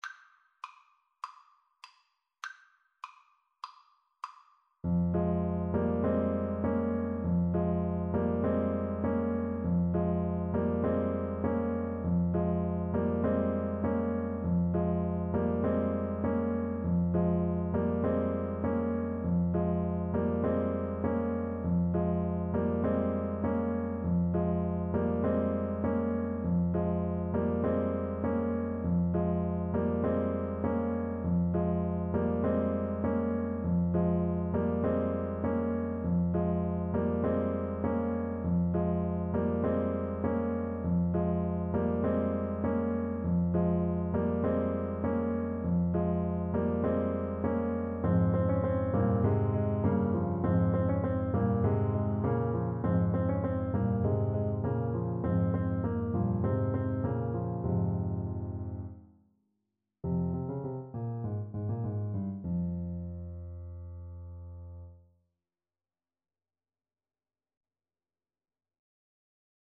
Play (or use space bar on your keyboard) Pause Music Playalong - Piano Accompaniment Playalong Band Accompaniment not yet available transpose reset tempo print settings full screen
F minor (Sounding Pitch) D minor (Alto Saxophone in Eb) (View more F minor Music for Saxophone )
Allegro (View more music marked Allegro)
4/4 (View more 4/4 Music)
Classical (View more Classical Saxophone Music)